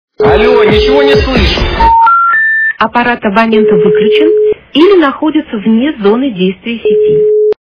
» Звуки » Смешные » Але, ничего не слышу - Аппарат абонента выключен или находится вне зоны сети
При прослушивании Але, ничего не слышу - Аппарат абонента выключен или находится вне зоны сети качество понижено и присутствуют гудки.